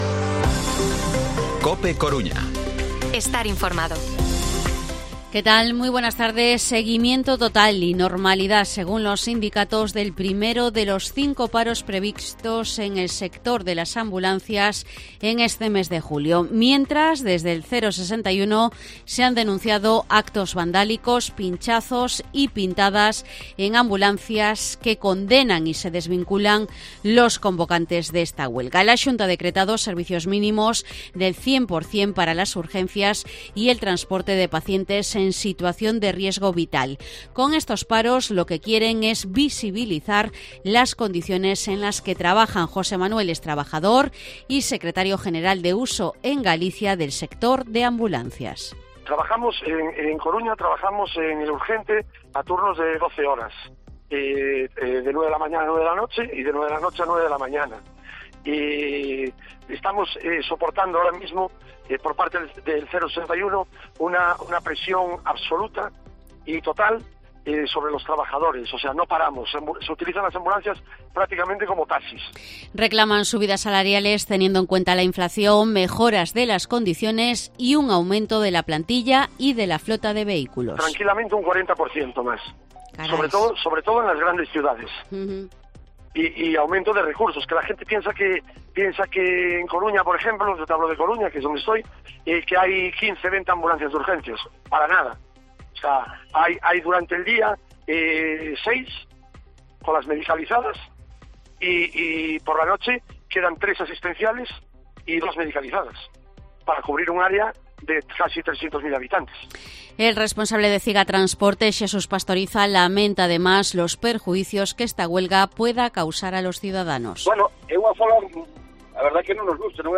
Informativo mediodía 6/07/2023 De 14:20 a 14:30 horas